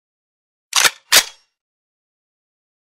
دانلود آهنگ پر کردن تفنگ 3 از افکت صوتی اشیاء
دانلود صدای پر کردن تفنگ 3 از ساعد نیوز با لینک مستقیم و کیفیت بالا
جلوه های صوتی